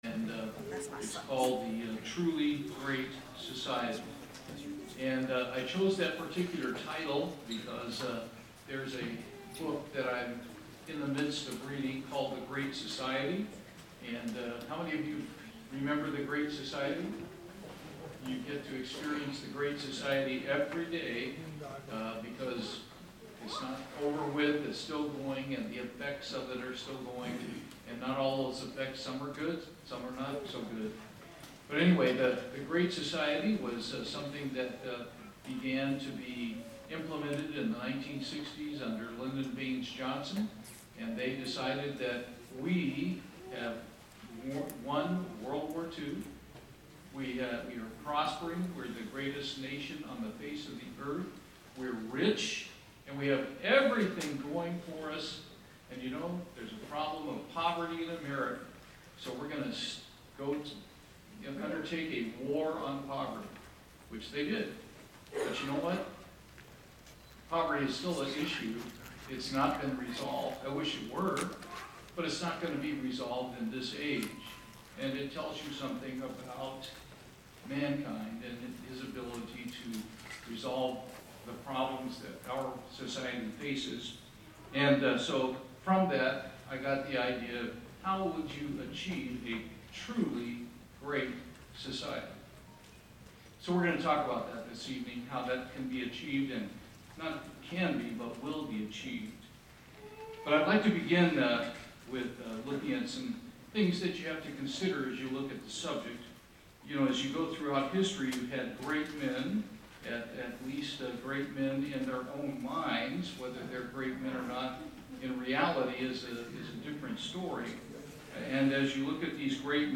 This sermon was given at the Bastrop, Texas 2022 Feast site.